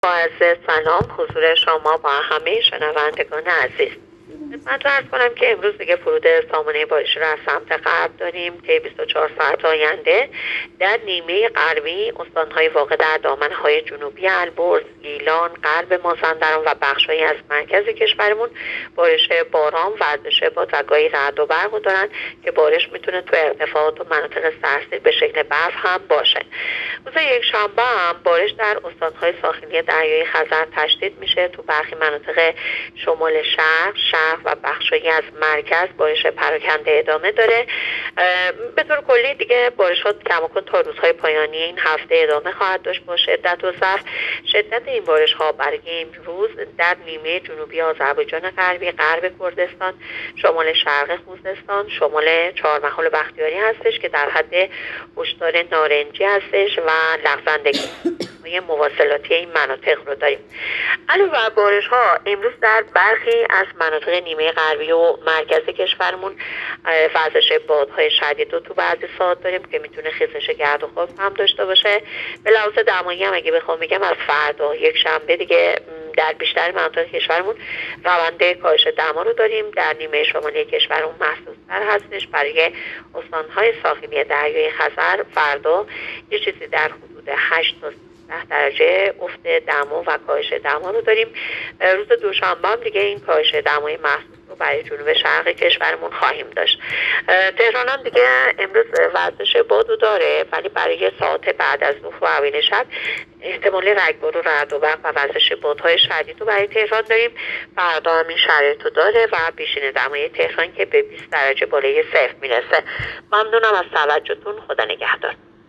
گزارش رادیو اینترنتی از آخرین وضعیت آب و هوای ۲۵ بهمن؛